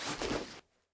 equip_leather6.ogg